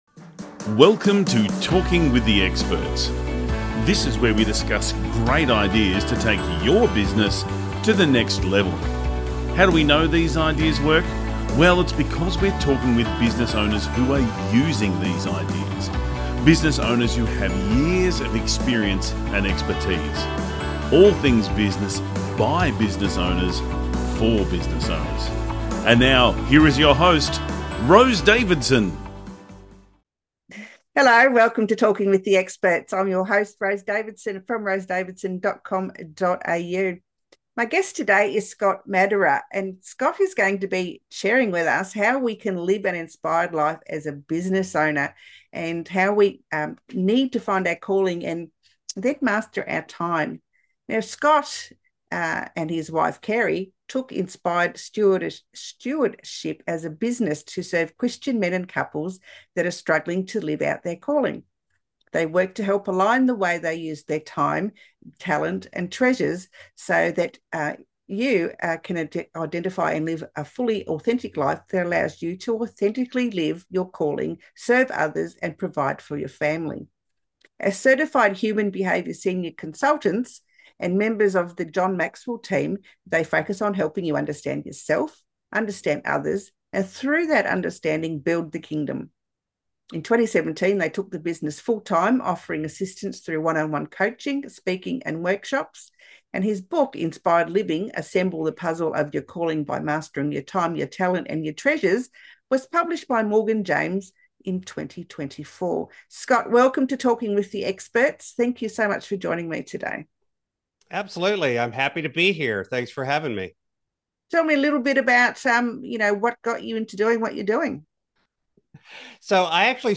💡 Three Key Points from the Interview: